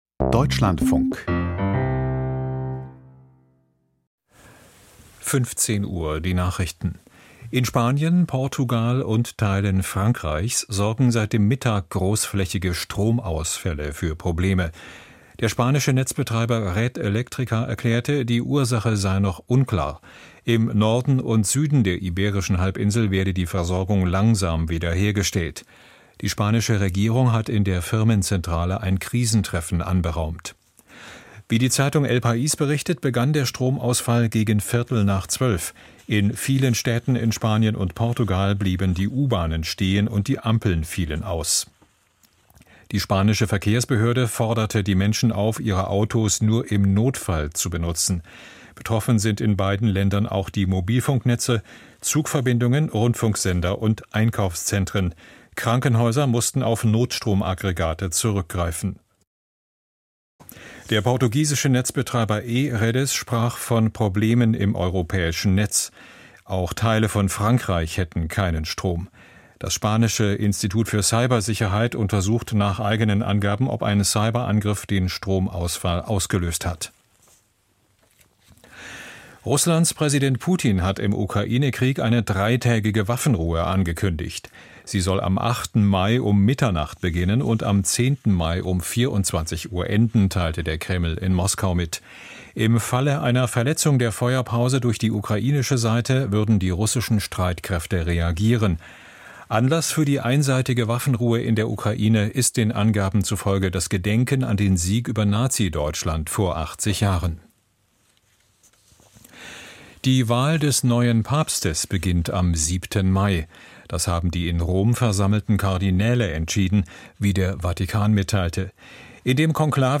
Die Deutschlandfunk-Nachrichten vom 28.04.2025, 15:00 Uhr